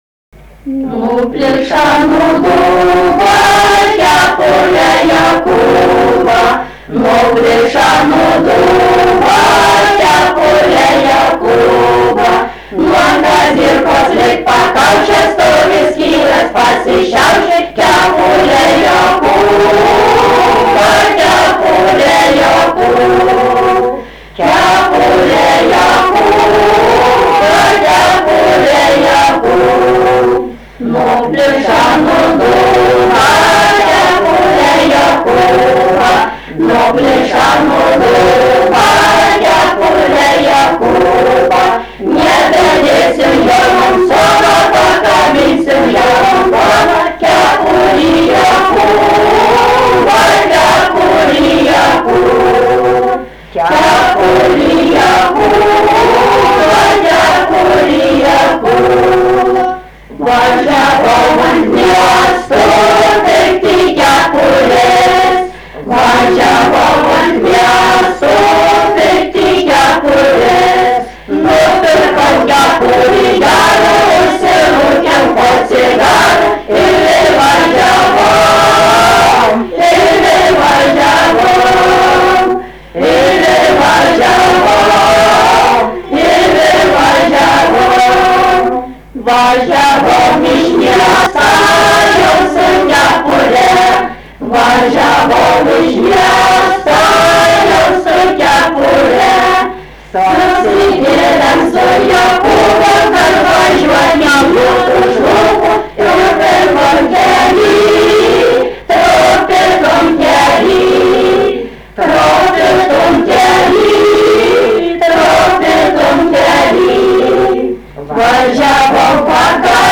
Moterų grupė
daina
Medeikiai
vokalinis